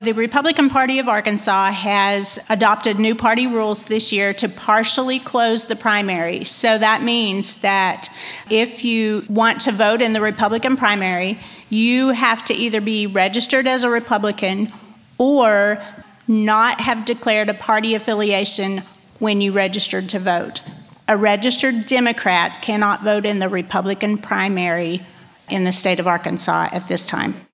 At a recent political forum hosted by KTLO, Classic Hits and The Boot News Baxter County Clerk Canda Reese says her office is ready for this voting season and explained what a partially closed primary will mean for voters.